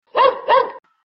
狗 | 健康成长
goujiao.mp3